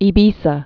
(ē-bēsə, ē-vēthä)